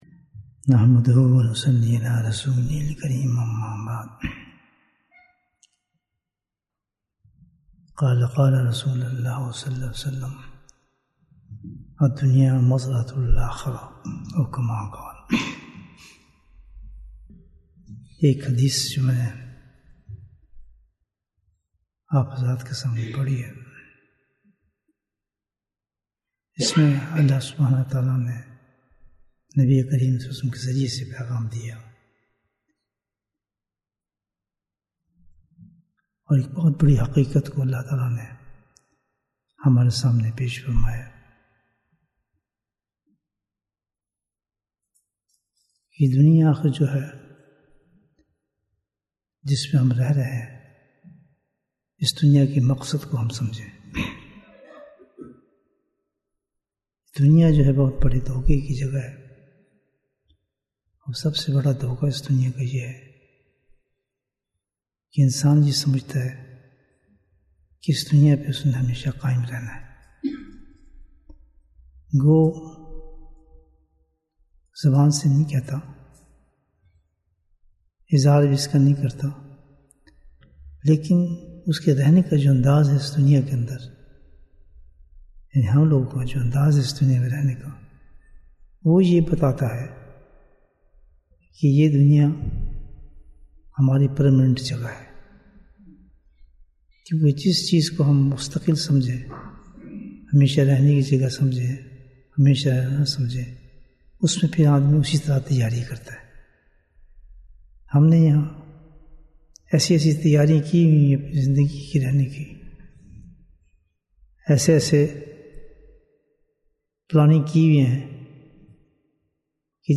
Bayan, 27 minutes 31st May, 2023 Click for English Download Audio Comments What is the Reality of Dunya?